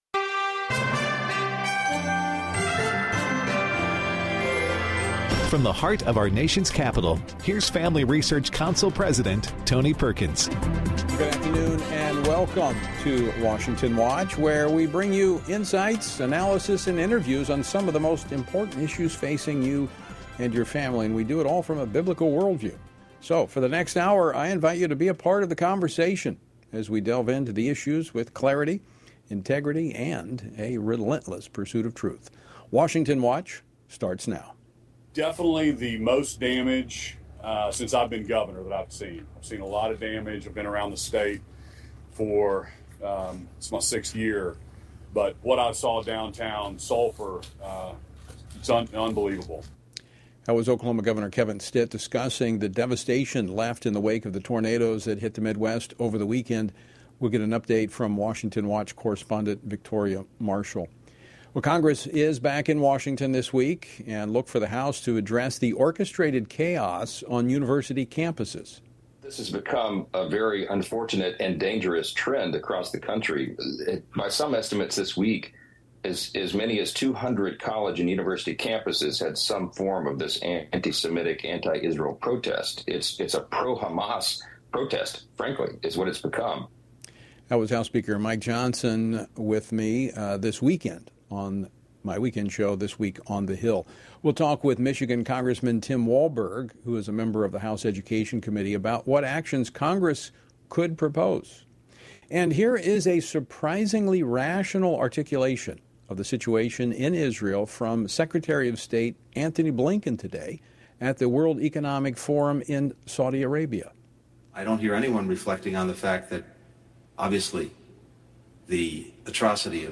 Tim Walberg, U.S. Representative for the 5th District of Michigan, responds to the continuing anti-Semitic protests on college campuses and discusses Secretary of State Antony Blinken’s Middle East trip.